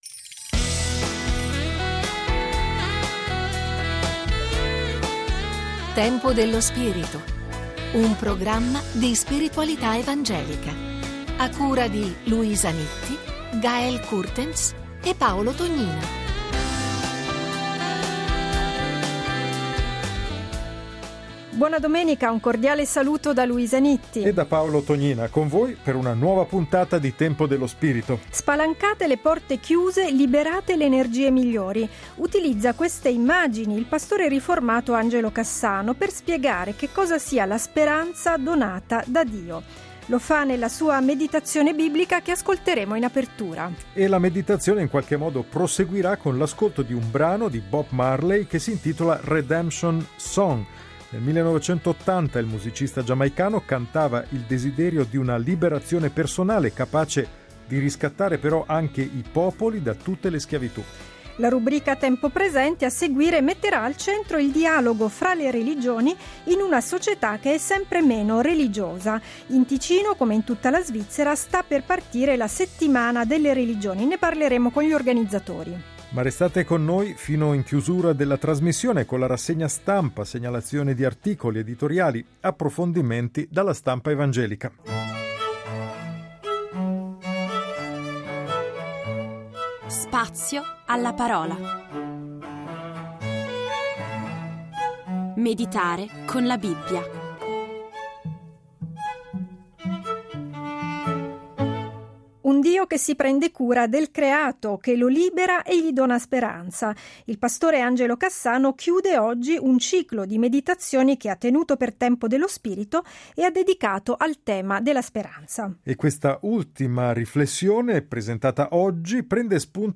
Meditazione biblica